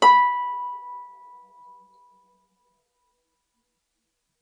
KSHarp_B5_mf.wav